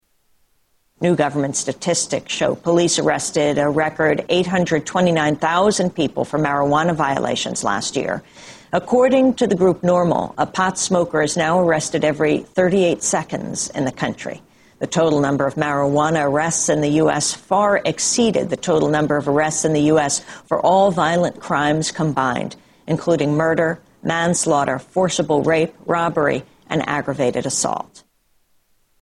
Tags: Historical Top 11-20 Censored News Stories 2009 Censored News Media News Report